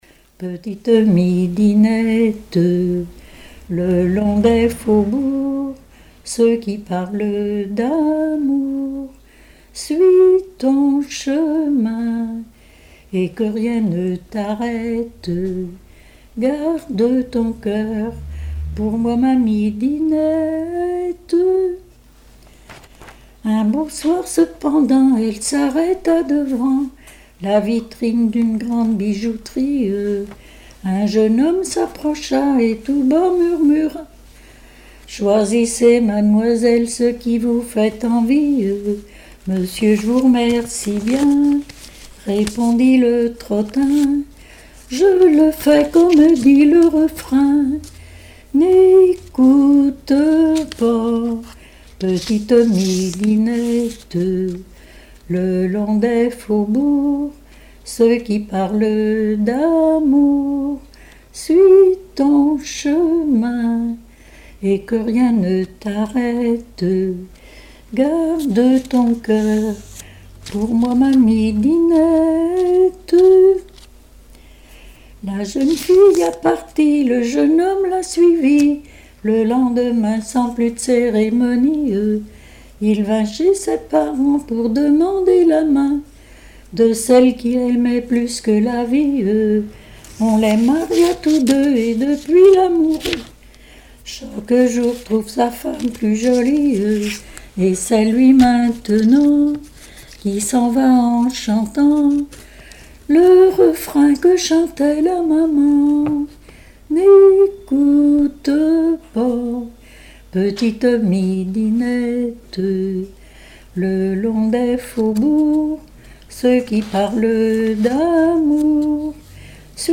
Genre strophique
Témoignages et chansons
Pièce musicale inédite